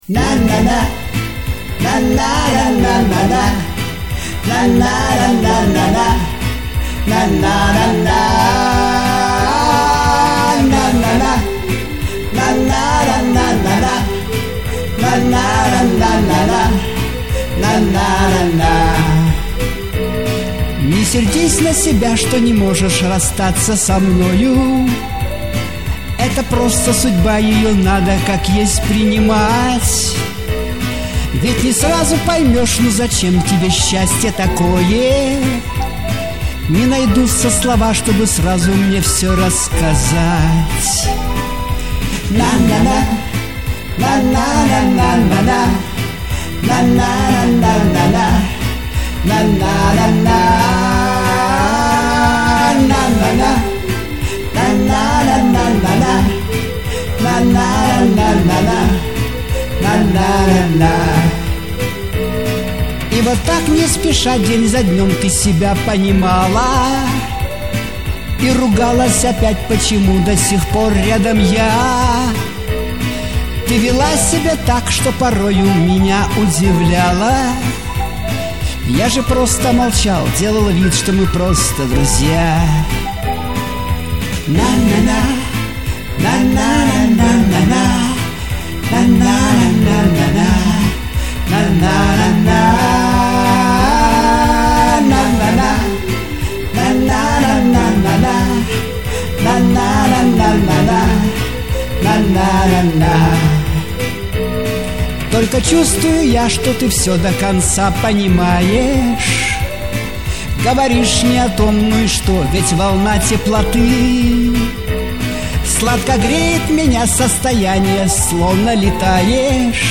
Лирическая музыка